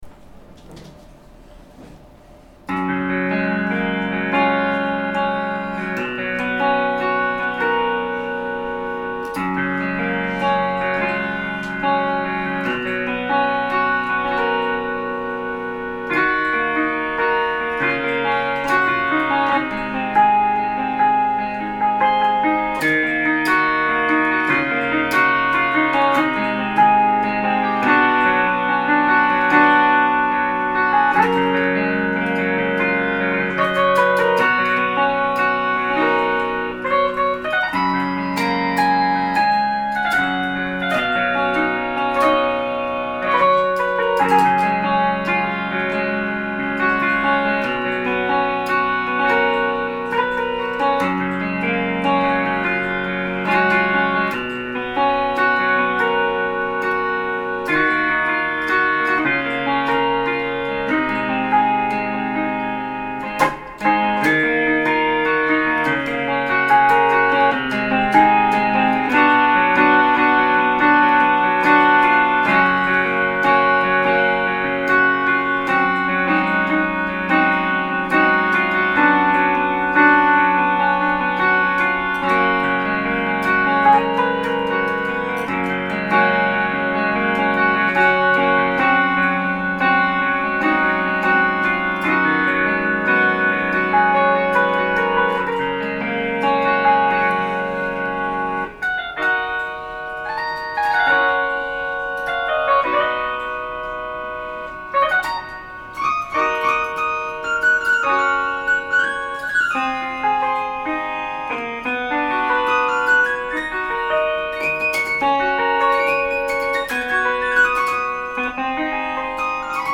Me exso... esta vez con música, es lo que me pasa cuando estoy solo en mi cuarto.
random_jam.mp3